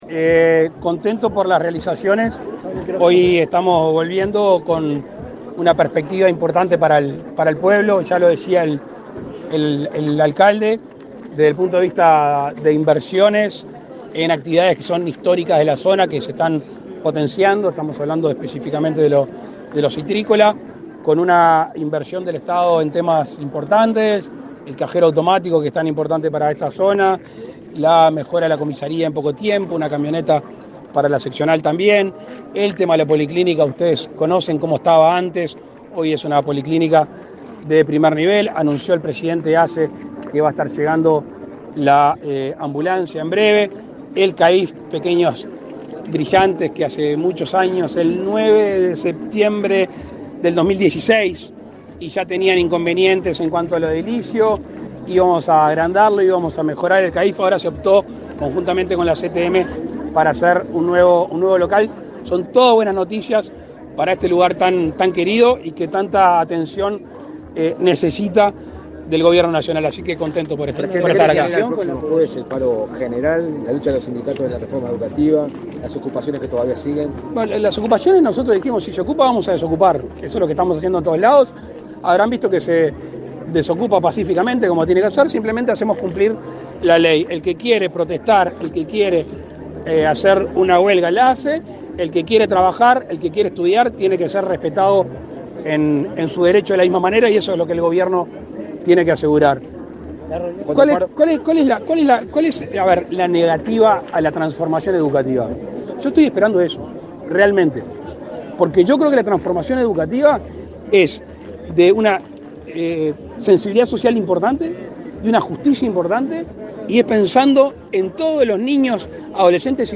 Declaraciones a la prensa del presidente de la República, Luis Lacalle Pou
Declaraciones a la prensa del presidente de la República, Luis Lacalle Pou 13/09/2022 Compartir Facebook X Copiar enlace WhatsApp LinkedIn Tras participar en el acto de inauguración de obras de remodelación y ampliación de la policlínica de Villa Constitución, en Salto, este 13 de setiembre, el mandatario realizó declaraciones a la prensa.